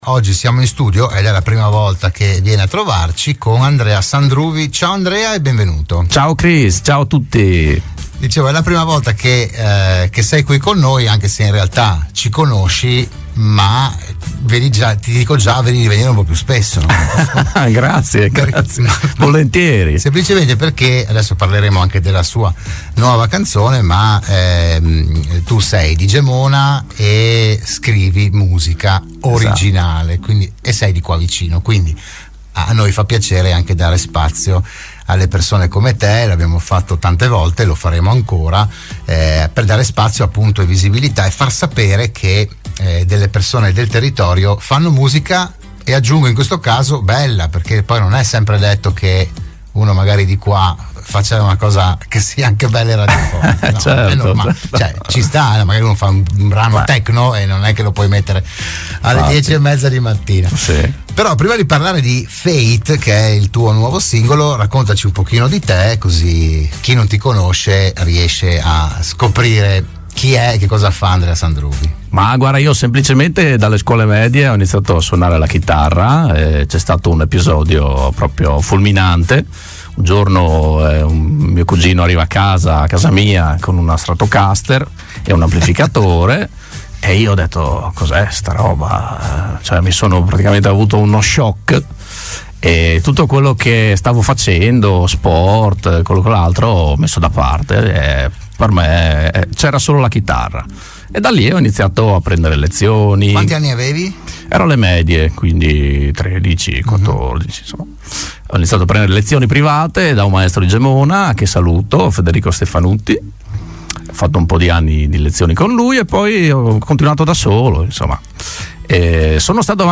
Il musicista gemonese ospite a Radio Studio Nord